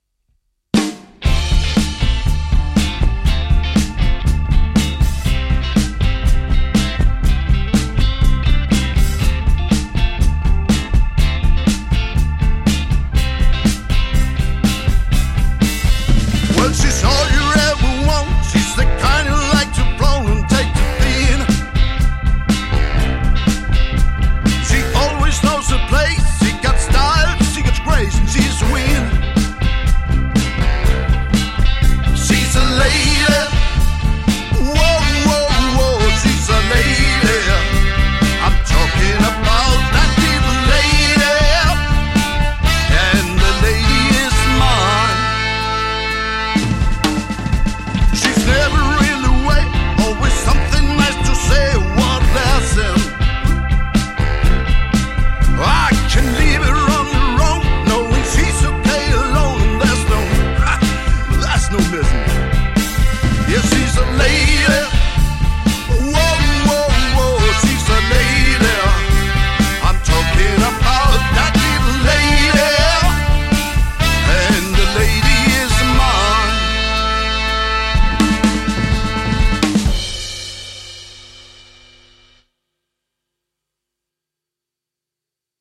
LIVE Koncert
• Coverband